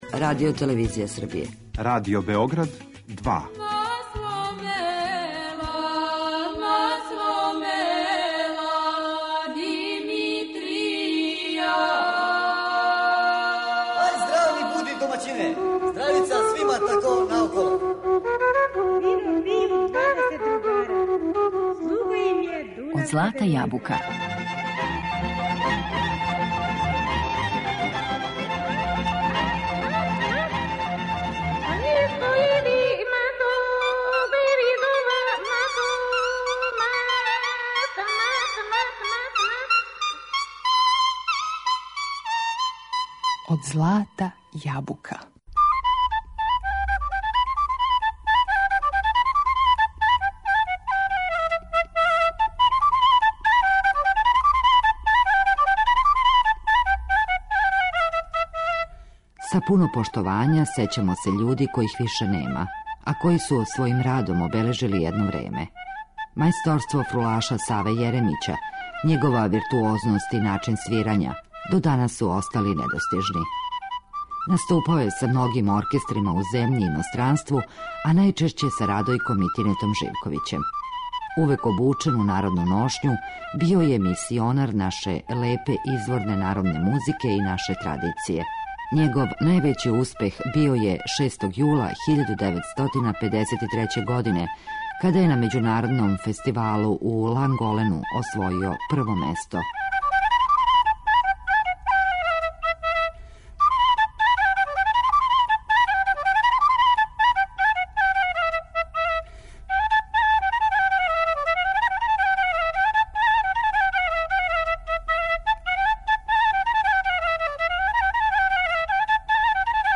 Увек обучен у народну ношњу, био је мисионар наше лепе изворне народне музике и традиције.